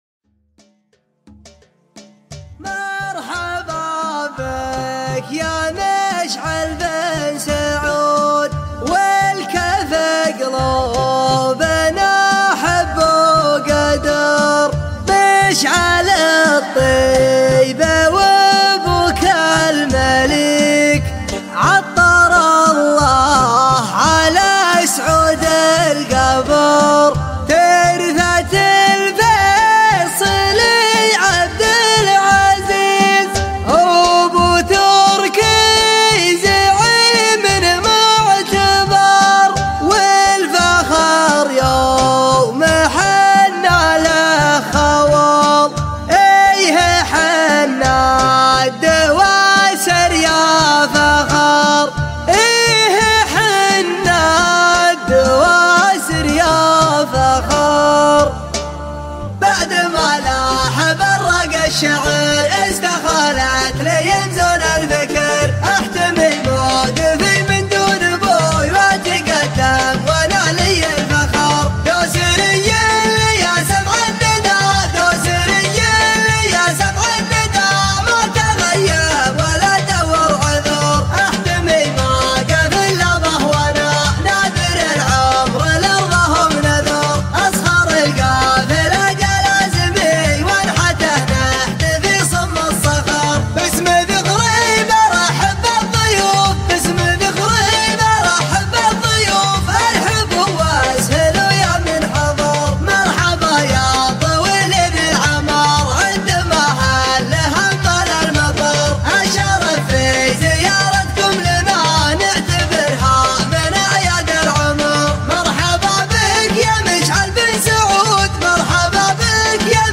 شيلة